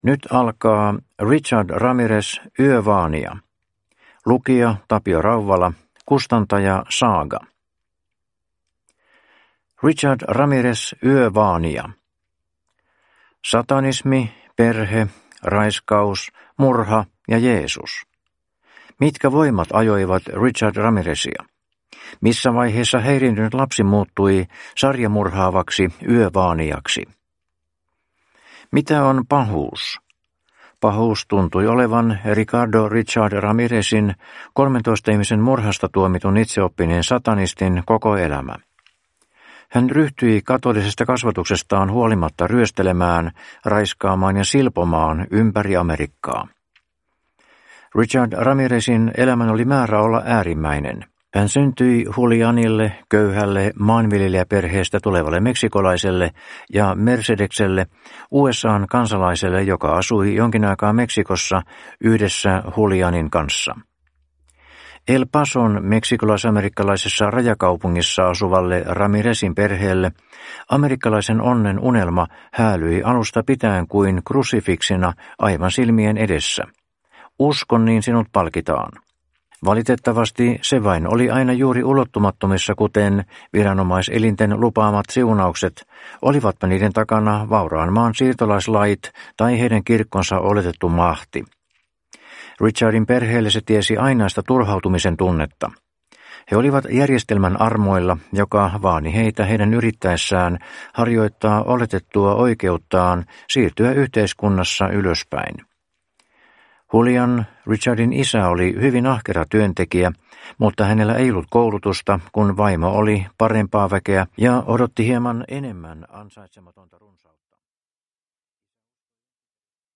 Richard Ramirez – Yövaanija (ljudbok) av Orage